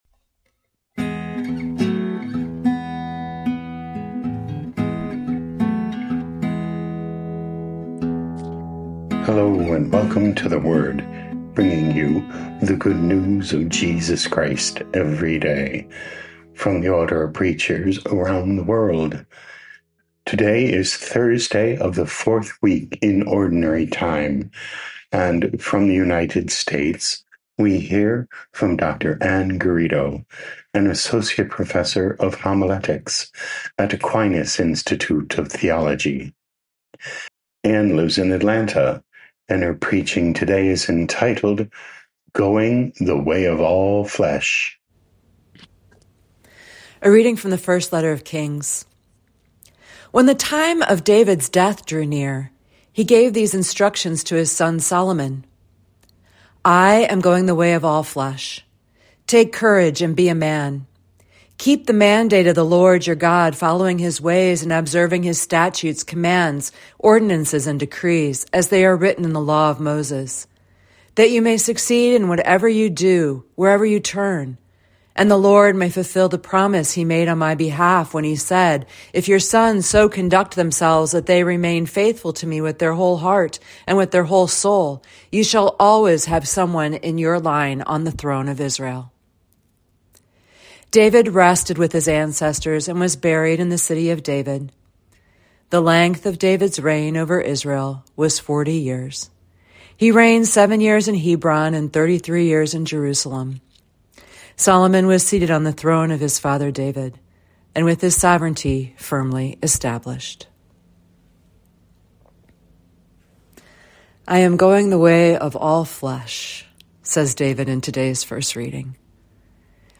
5 Feb 2026 Going the Way of All Flesh Podcast: Play in new window | Download For 5 February 2026, Thursday of week 4 in Ordinary Time, based on 1 Kings 2:1-4, 10-12, sent in from Atlanta, Georgia, USA.